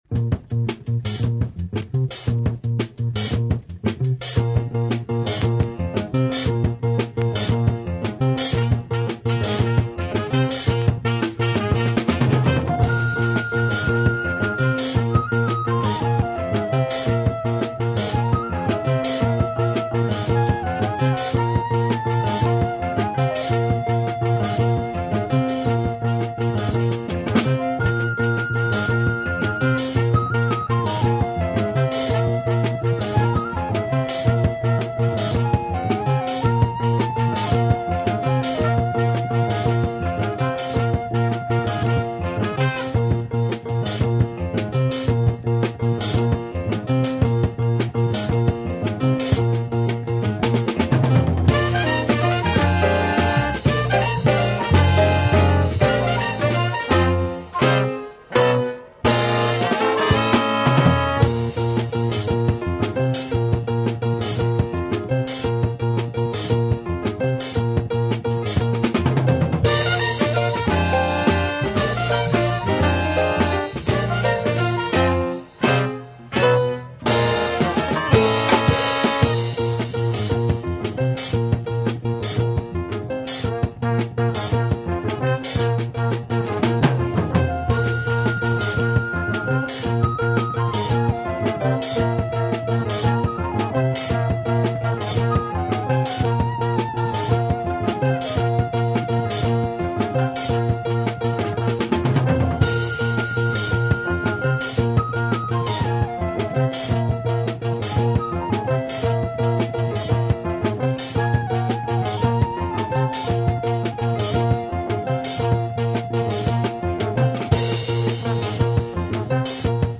breakbeat tune